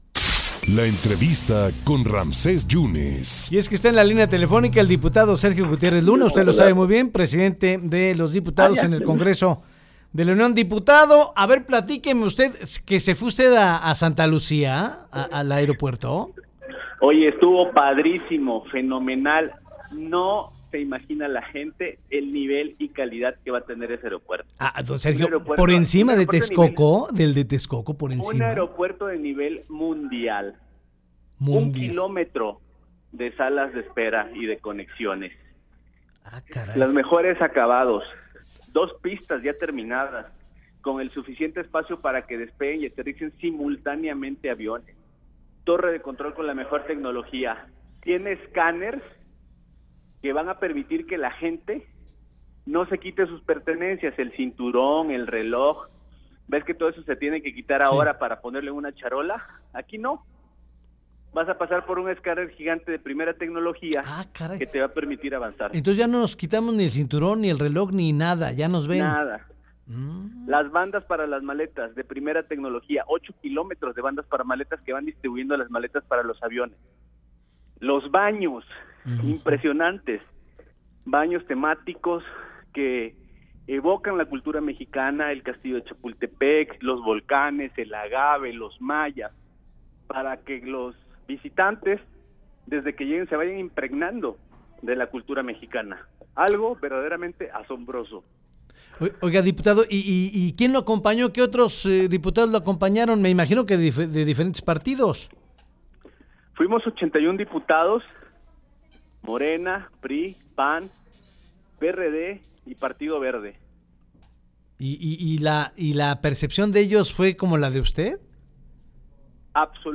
El diputado veracruzano Sergio Gutiérrez Luna comentó en entrevista para En Contacto de Avanoticias sobre la impresión que le ha causado el nuevo aeropuerto de Santa Lucía que visitó junto con sus homónimos de Morena y otros partidos esta mañana.